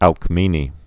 (ălk-mēnē)